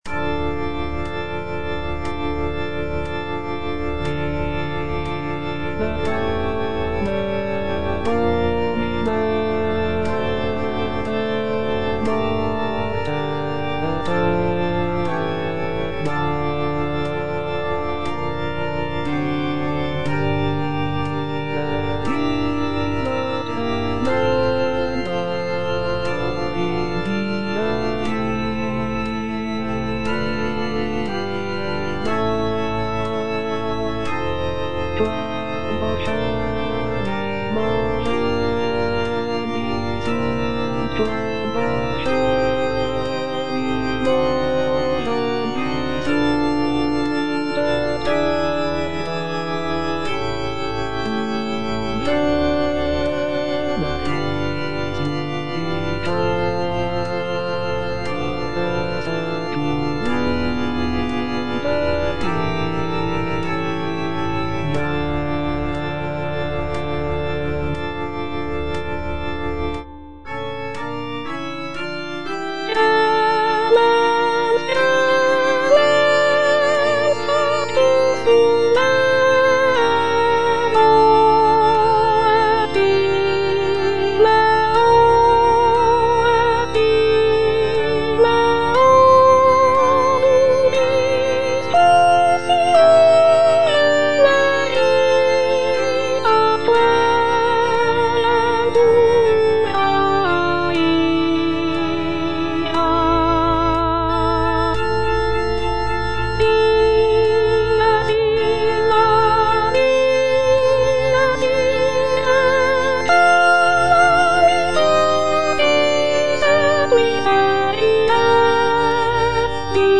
G. FAURÉ - REQUIEM OP.48 (VERSION WITH A SMALLER ORCHESTRA) Libera me - Soprano (Voice with metronome) Ads stop:
This version features a reduced orchestra with only a few instrumental sections, giving the work a more chamber-like quality.